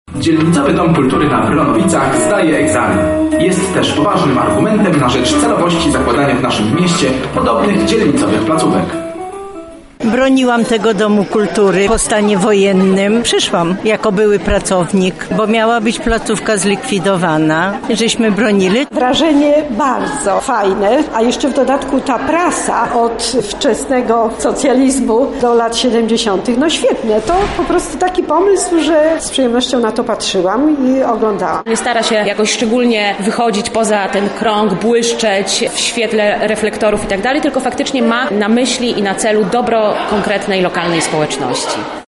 O wrażeniach z gali oraz swoich doświadczeniach związanych z tym miejscem opowiedzieli uczestnicy: